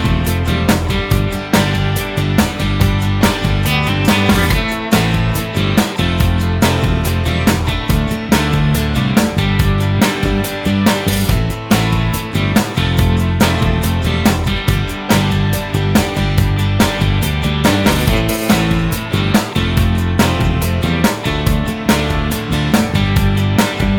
no Backing Vocals Indie / Alternative 4:27 Buy £1.50